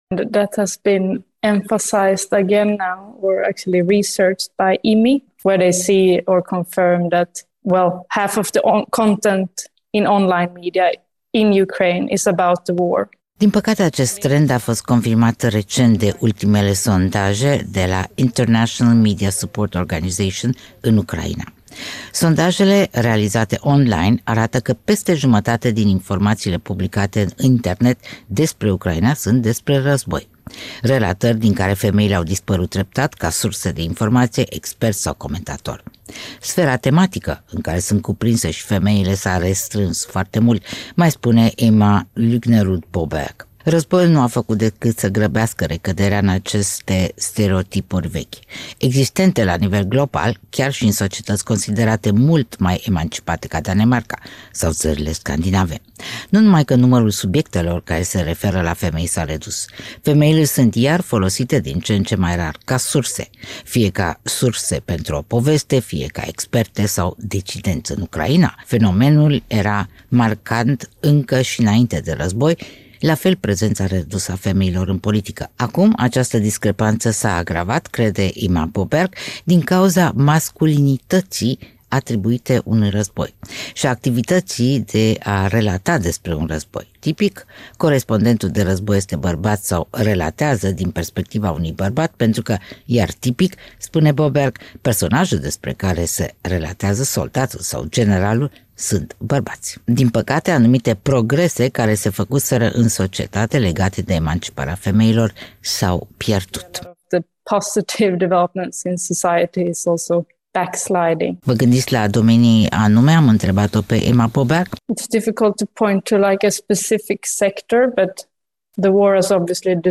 Panorama Interviu